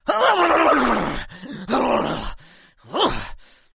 Звуки усилия
На этой странице собраны звуки усилия: стоны, напряжение, дыхание при физической нагрузке.